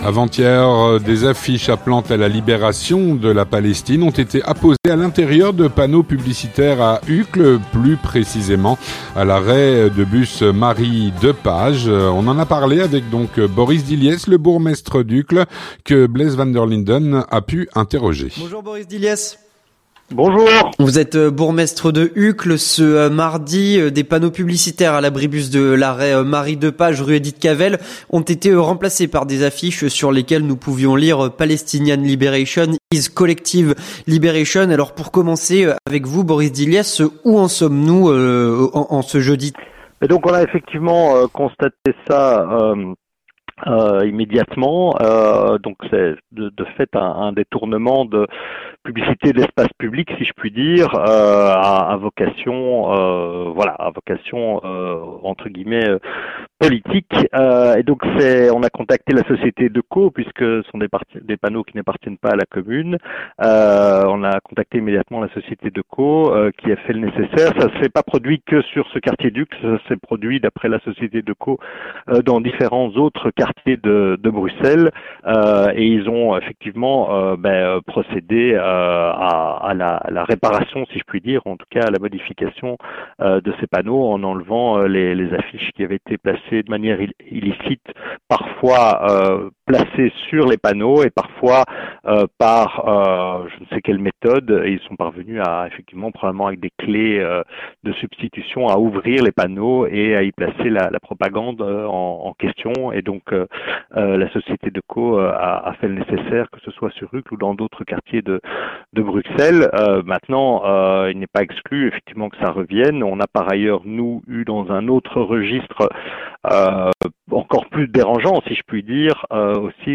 Avec Boris Dilliès, bourgmestre d’Uccle.